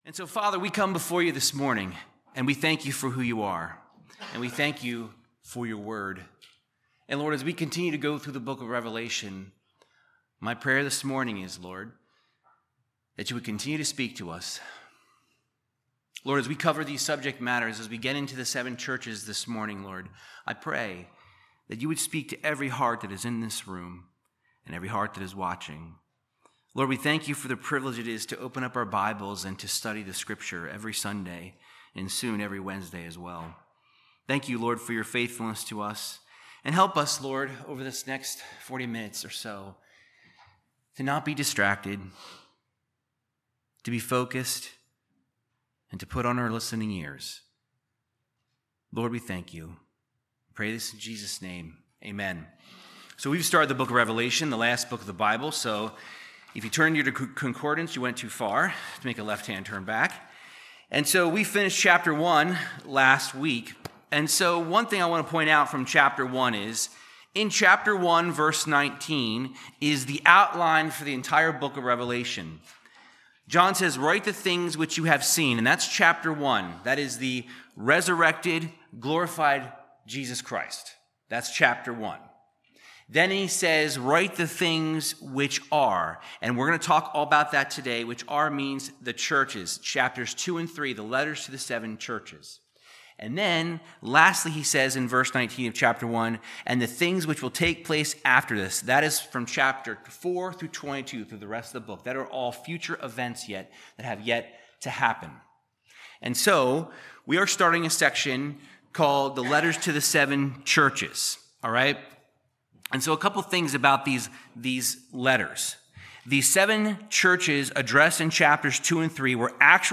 Verse by verse Bible teaching through Revelation 2:1-7